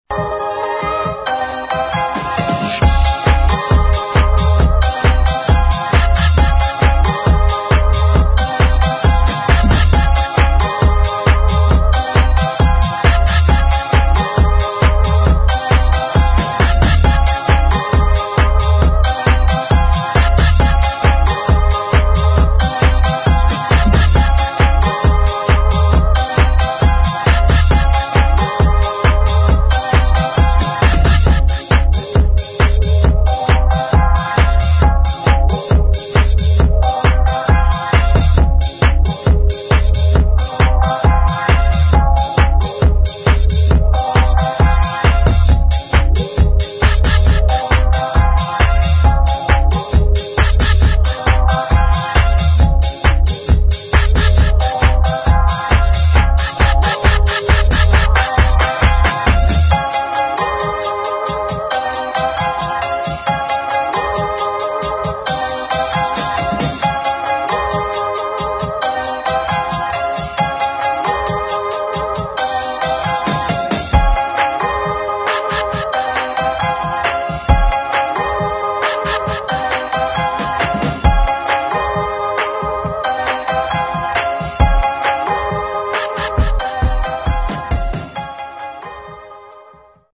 [ HOUSE | UK GARAGE ]